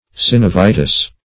Synovitis \Syn`o*vi"tis\, n.
synovitis.mp3